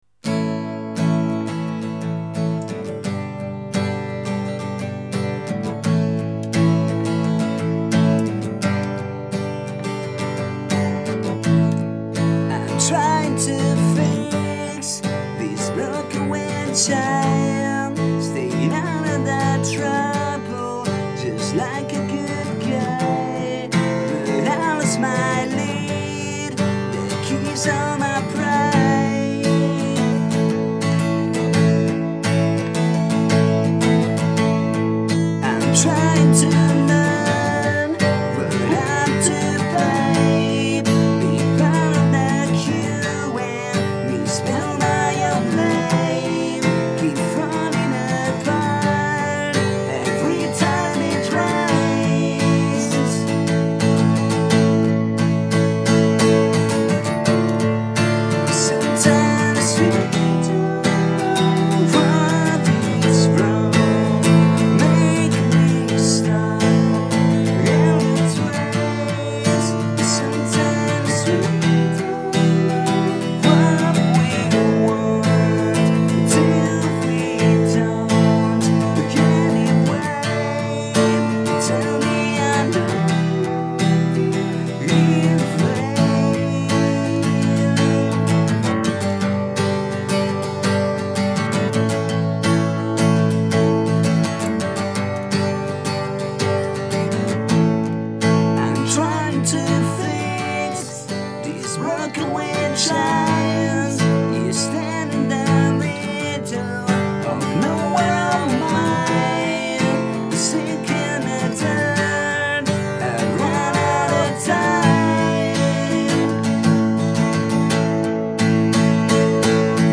un paio di versioni unplugged